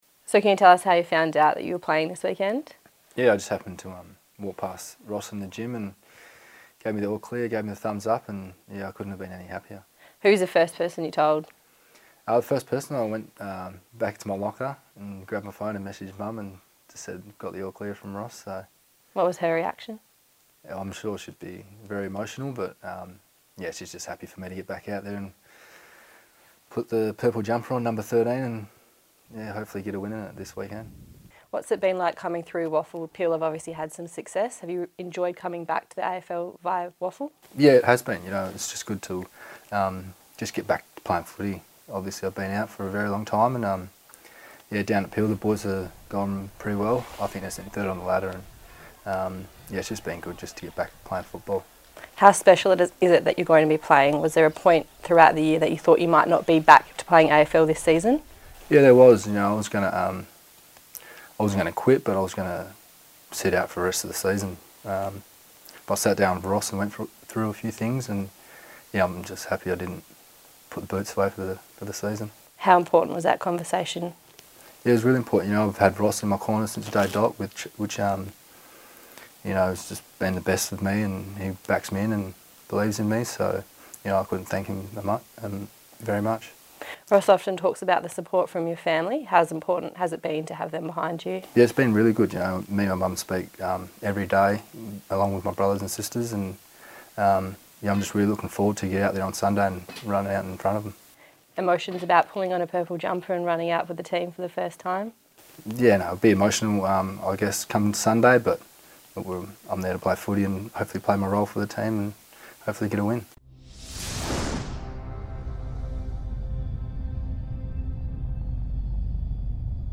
Harley Bennell chats to Docker TV ahead of his AFL return.